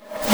just SNARES 3
rimshosfxlkd01REV.wav